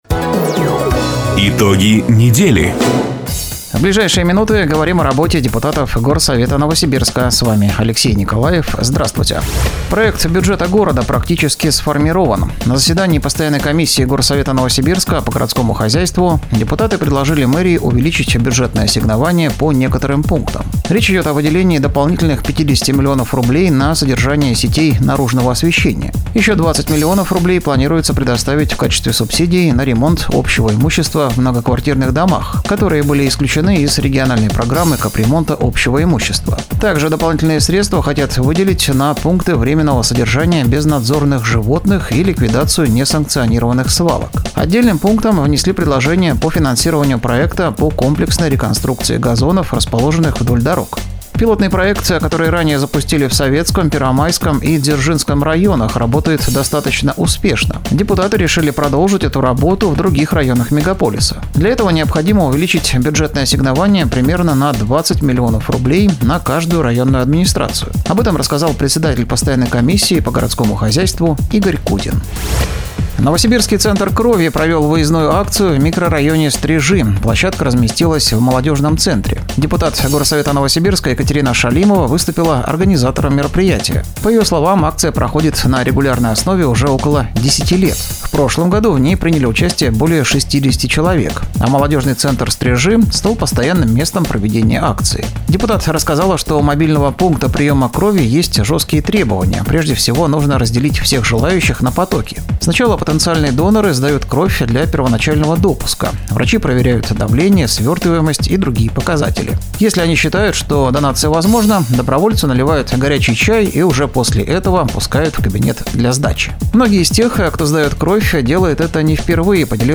Запись программы "Итоги недели", транслированной радио "Дача" 30 ноября 2024 года.